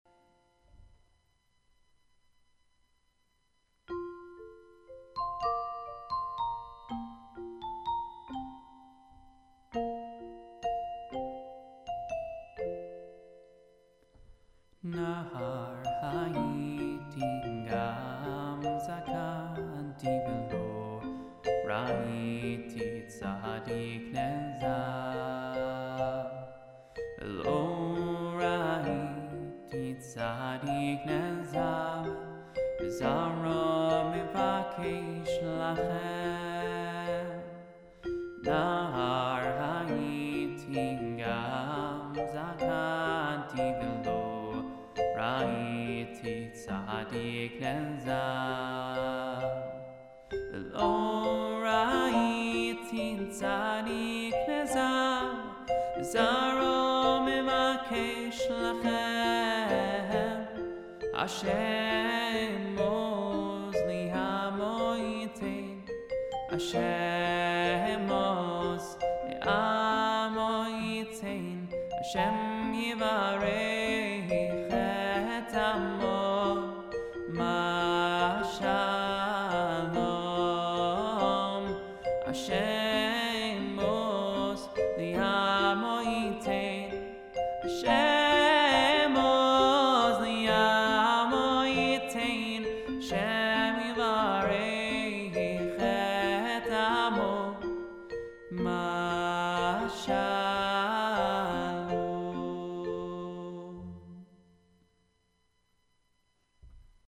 Lullabies